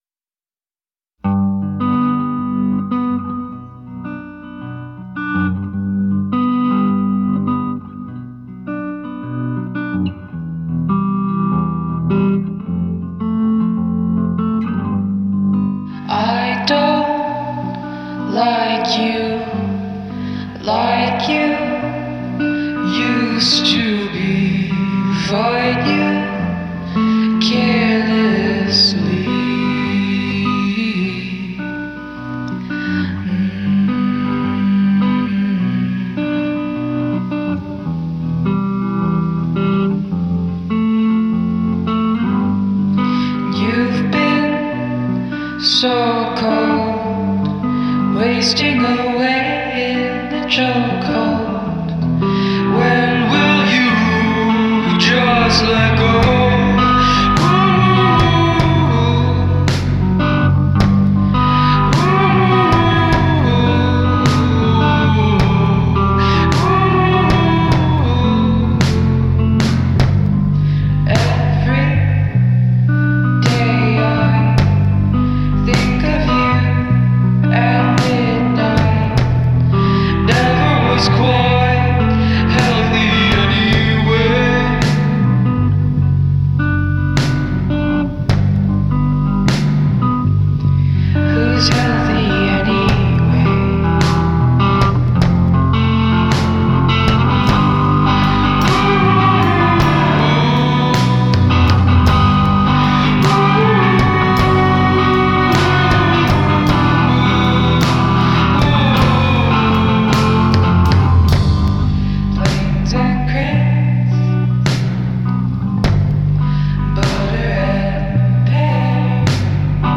breathtaking voice